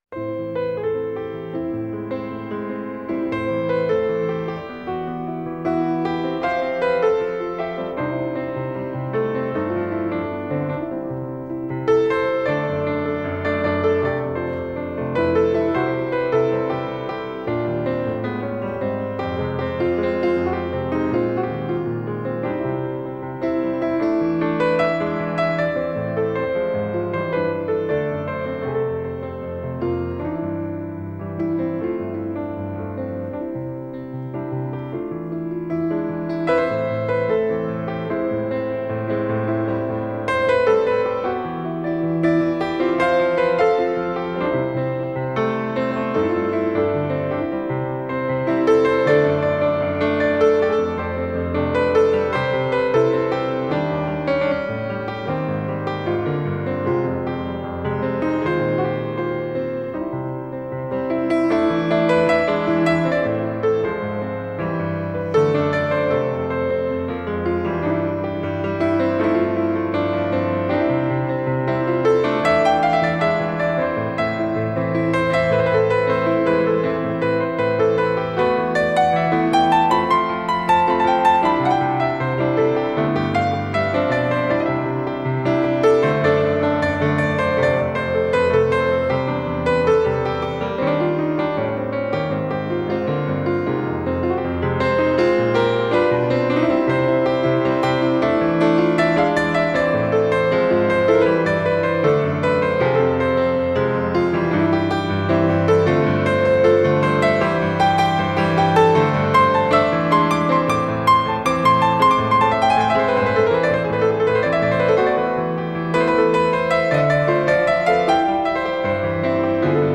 koncertalbuma